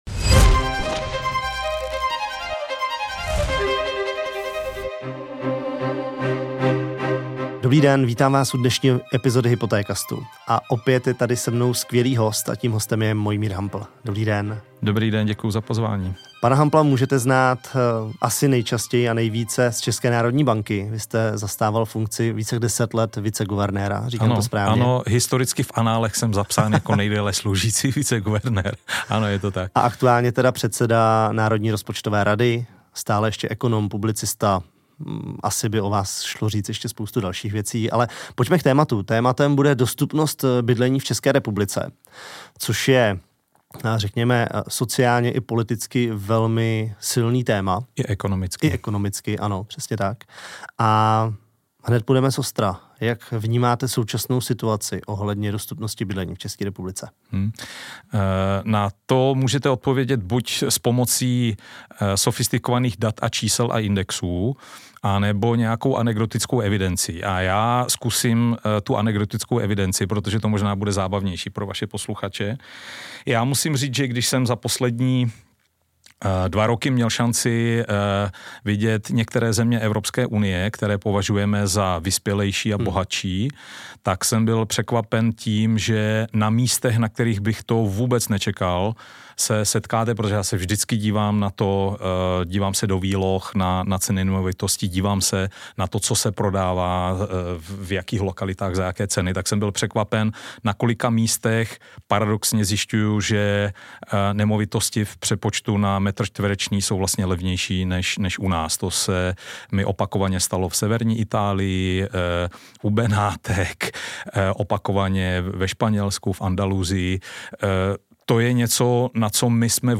Tento rozhovor jsme věnovali dostupností bydlení v Česku v porovnání s ostatními evropskými zeměmi. Mojmír Hampl podává fakta v komplexních souvislostech, ze kterých se dozvíte, jak se situace změnila za posledních zhruba 5 let.